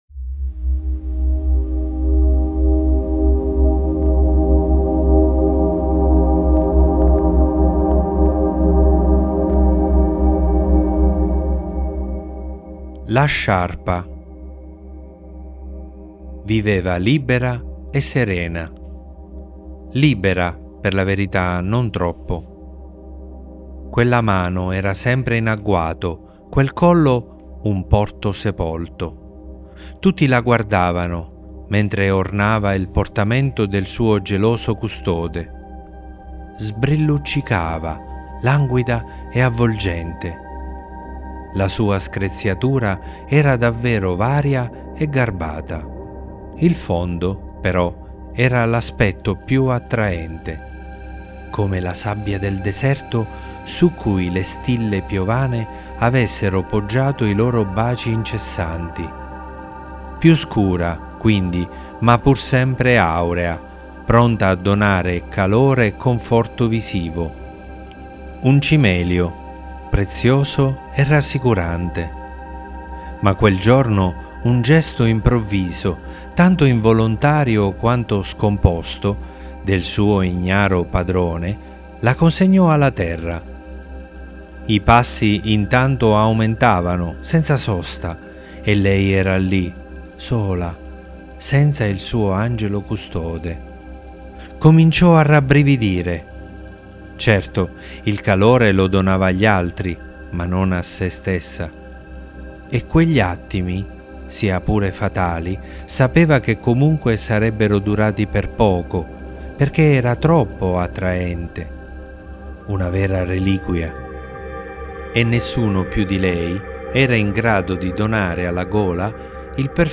Podcast Favole